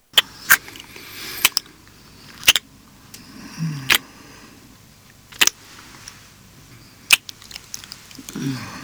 sex_kissing_02.wav